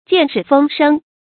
見事風生 注音： ㄐㄧㄢˋ ㄕㄧˋ ㄈㄥ ㄕㄥ 讀音讀法： 意思解釋： 謂遇事雷厲風行。